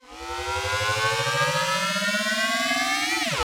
MB Trans FX (9).wav